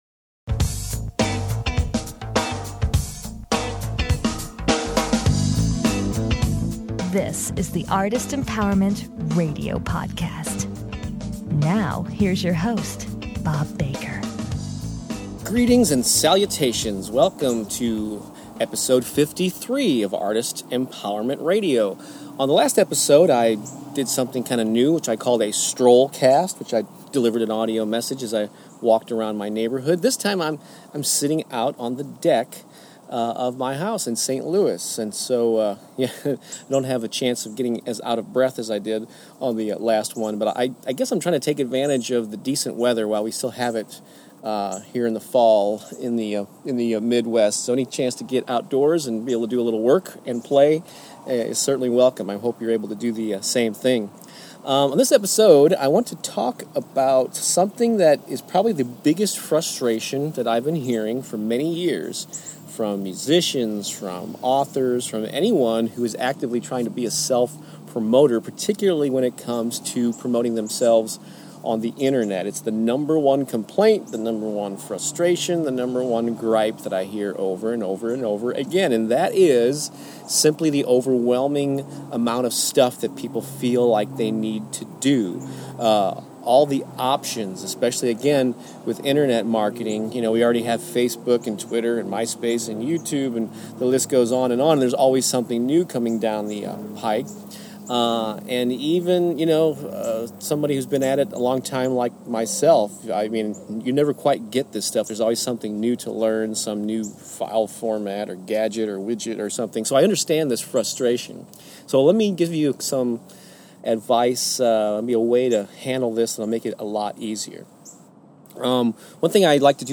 The show intro music is the beginning groove from "Fickle Mind"